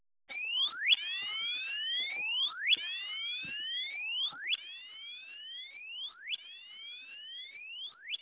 超軽量機体発見ブザー
そこで、発振周波数を低い周波数から高い周波数へと連続的に変化させて、これを繰り返すことにしました。
音の周波数を連続的に変化させているので、ちょっと変わった音色になりました。
音色は、こんな感じです。
ar70_tone1.wav